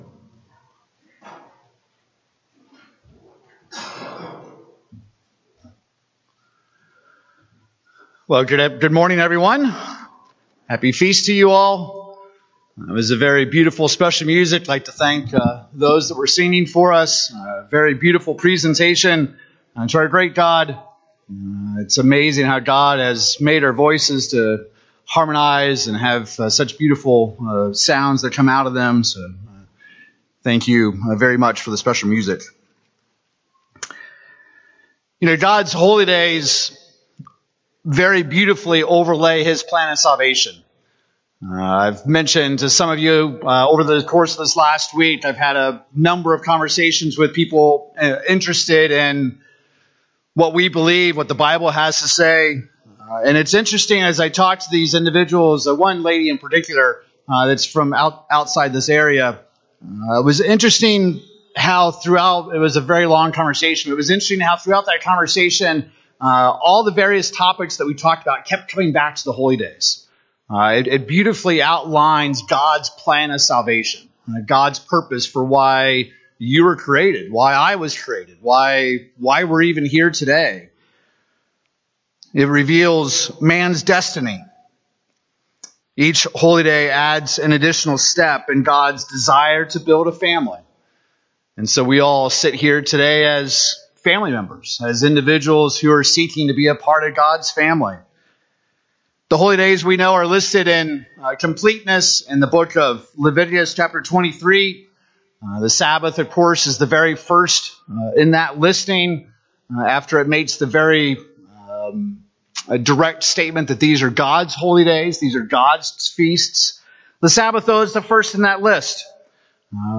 There are many theories about what the Holy Spirit is. In this sermon, we'll look in depth at John 14 and see what Jesus himself says about the spirit.